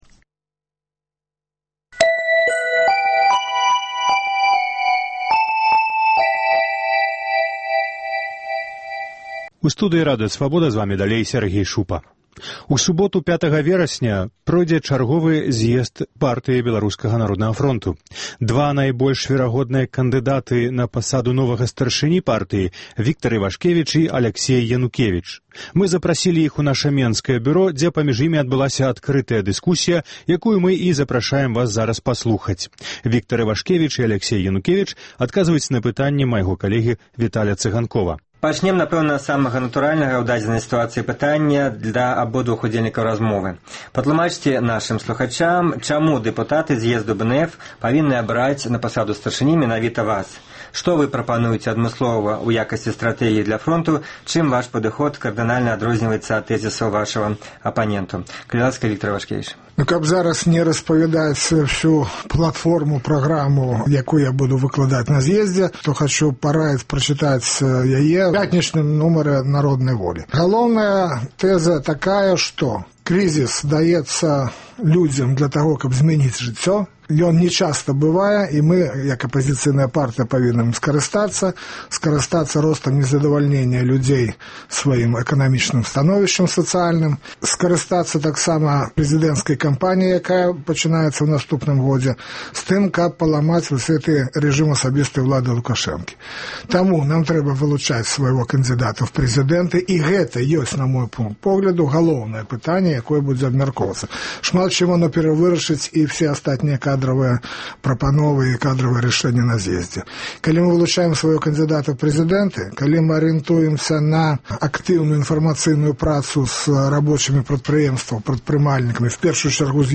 Дэбаты